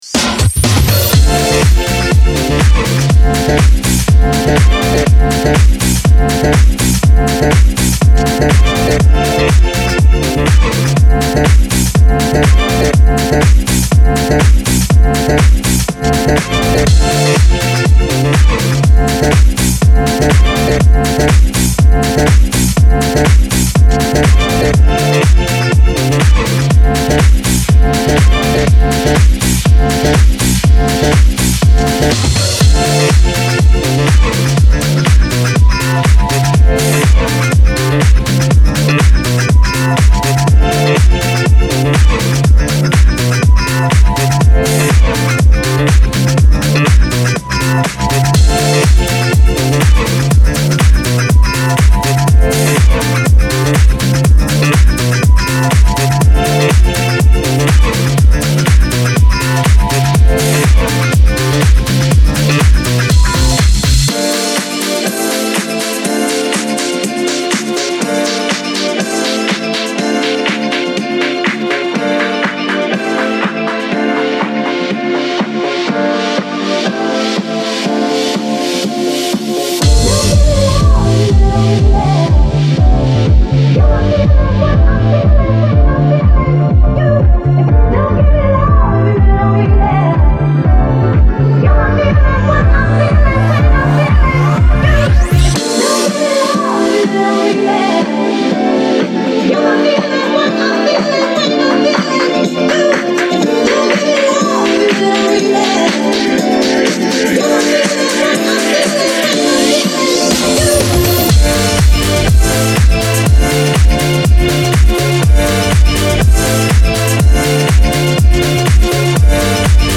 Jackin House Radio